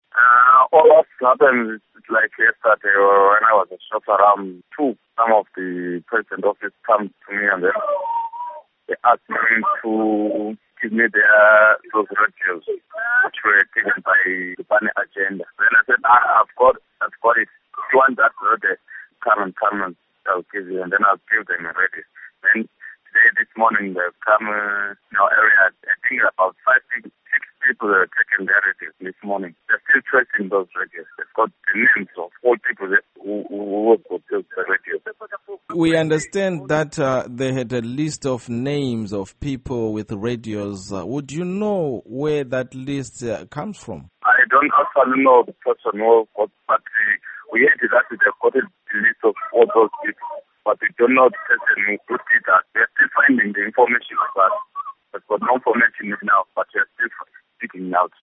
Interview With Lupane Villager